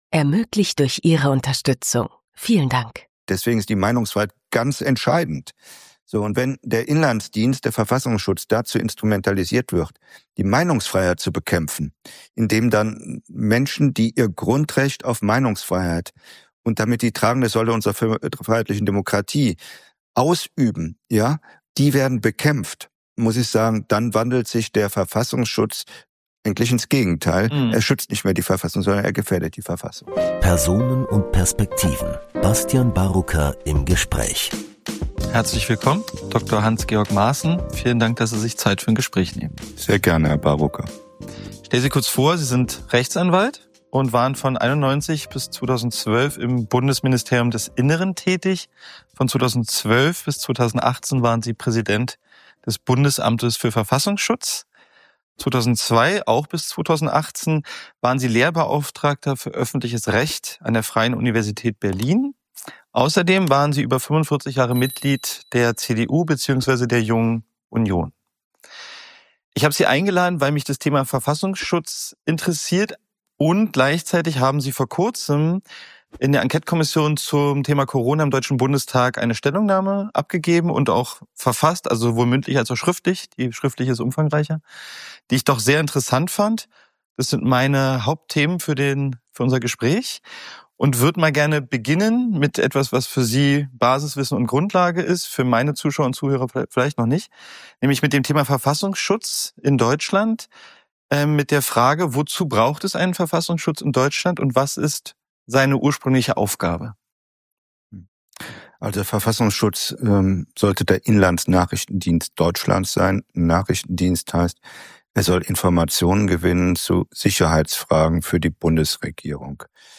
In unserem Gespräch geht es um den Sinn und die Notwendigkeit eines Verfassungsschutzes, der beispielsweise Kritiker der Corona-Politik als Delegitimierer des Staates einstuft und investigative Journalisten beobachtet. Laut Maaßen ist die aktuell größte Bedrohung für die freiheitlich-demokratische Grundordnung neben der Arbeitsweise des Verfassungsschutzes die anhaltende Einschränkung der Meinungsfreiheit, die zu einem schwindenden Pluralismus führt.